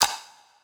Perc [4].wav